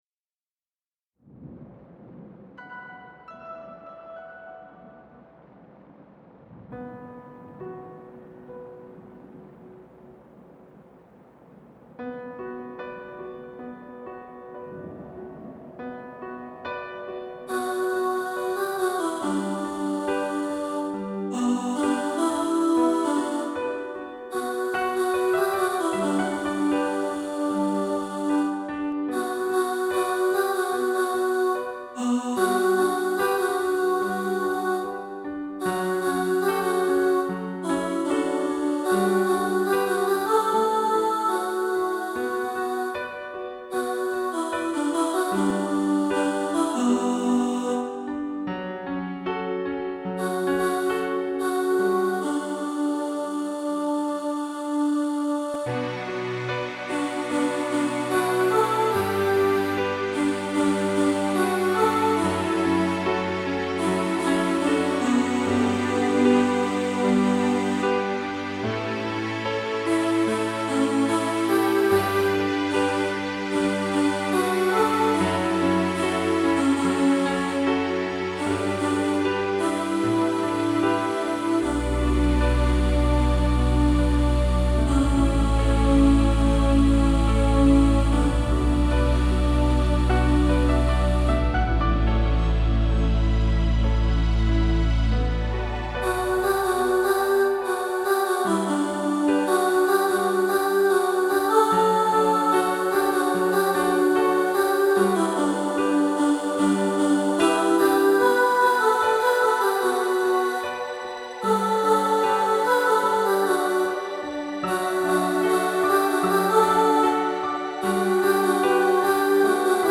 Power-Of-Love-Alto.mp3